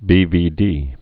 (bēvēdē)